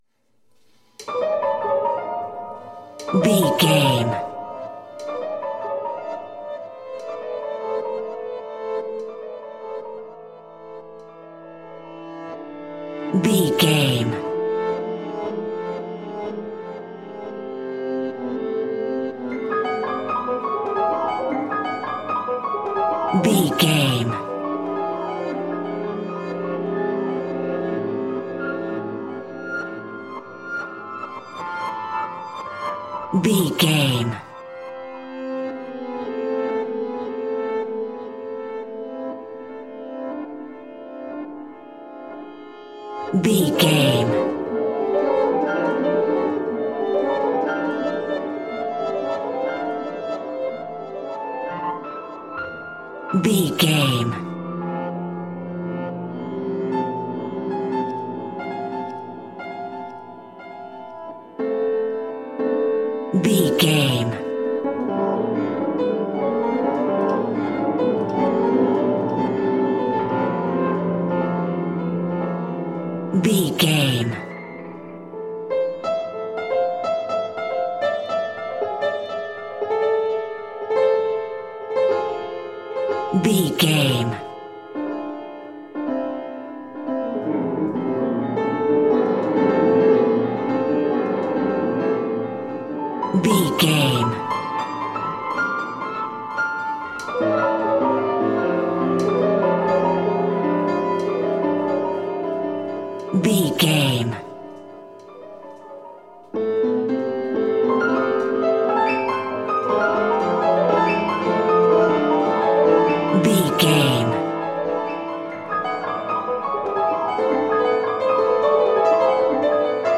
Thriller
Aeolian/Minor
Fast
scary
ominous
dark
suspense
eerie
driving
Acoustic Piano